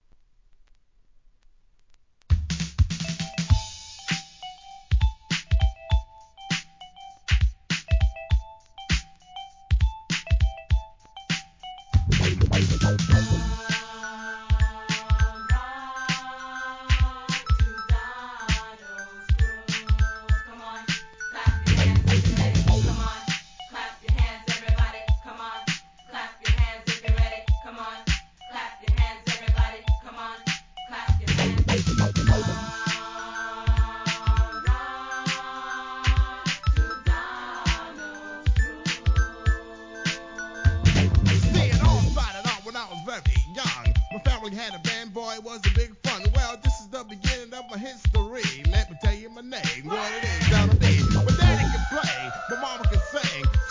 HIP HOP/R&B
1984年のエレクトロOLD SCHOOL!!